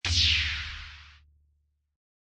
swing-2.wav — alternate swing. Also plays on task completion.